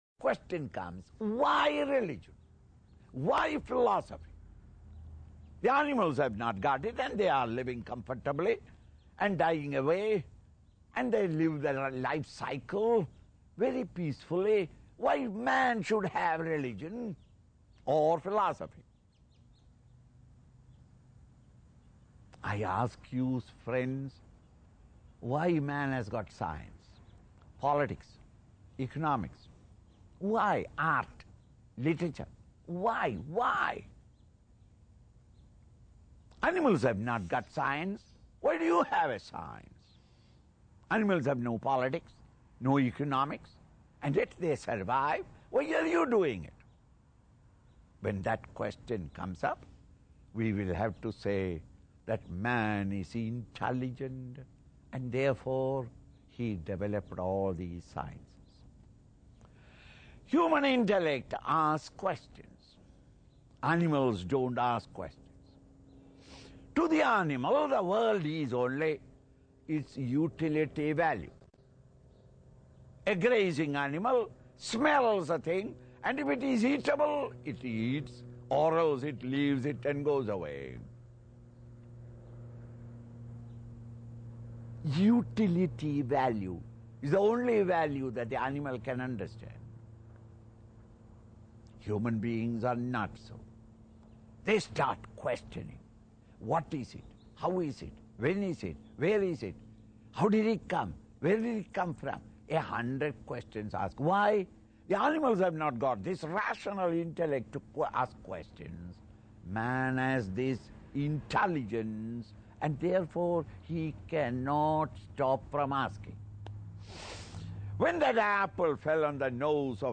Logic of Spirituality CD Talk by Swami Chinmayananda, An introduction to Vedanta